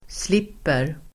Uttal: [sl'ip:er]